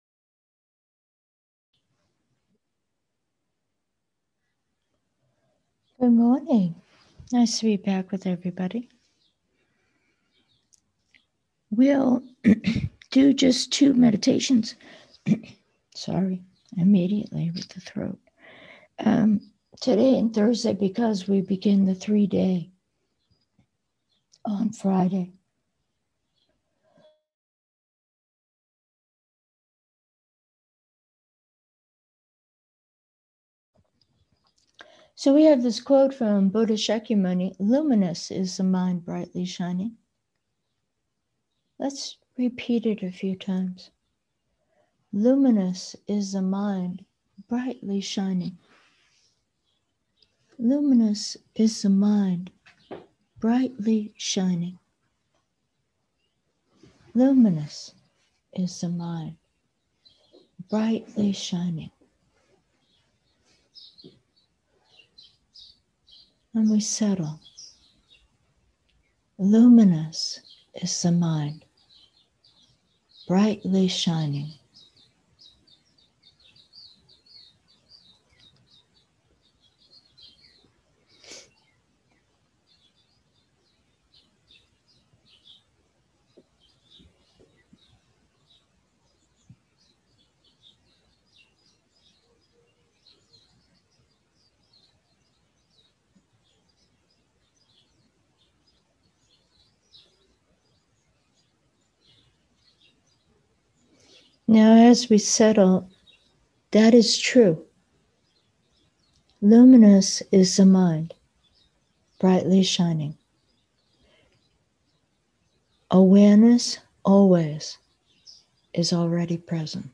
Meditation: luminous is the mind